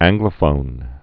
(ănglə-fōn)